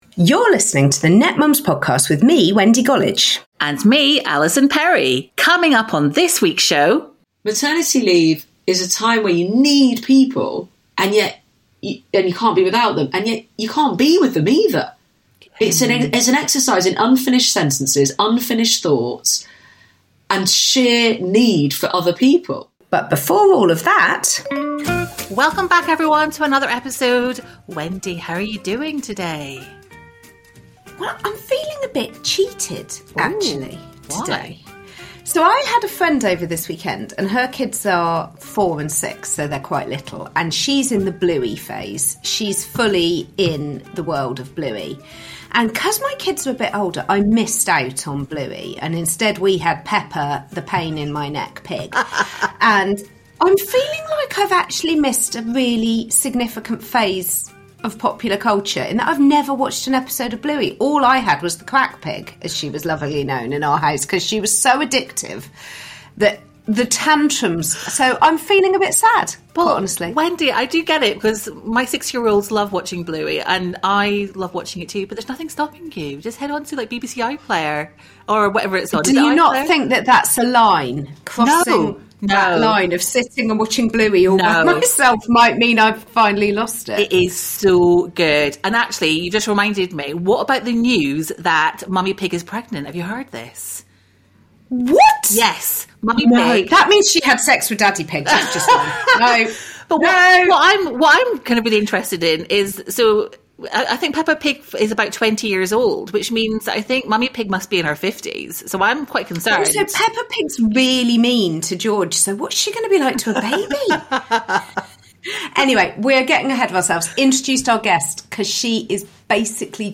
Emma, a mother of two, shares her experiences surrounding maternity leave and the often-unspoken realities of motherhood. The conversation includes: - The Reality of Maternity Leave: Emma discusses the challenges of feeling both isolated and in need of connection during this transformative period.